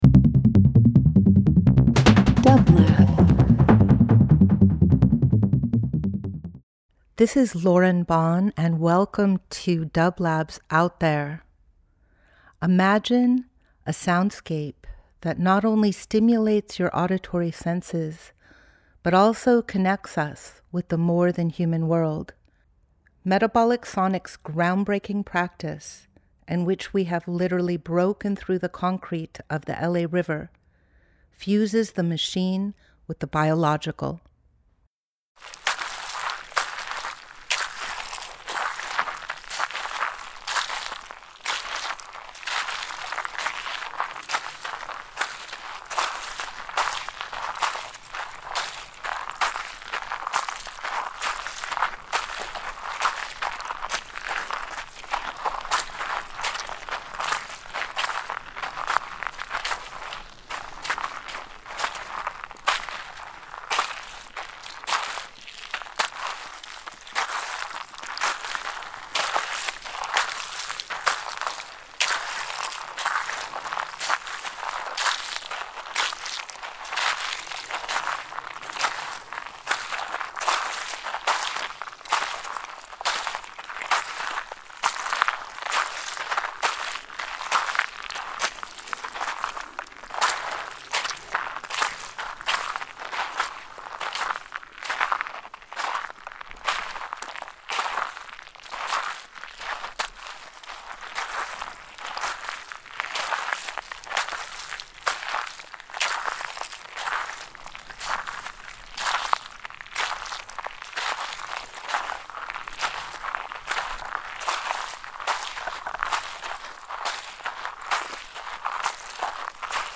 Each week we present field recordings that will transport you through the power of sound.
Metabolic Sonics Metabolic Studio Out There ~ a field recording program 03.26.26 Experimental Field Recording Voyage with dublab into new worlds.
Listen to footsteps (and hoofsteps) as Metabolic Sonics explore the watershed from which Los Angeles gets its water.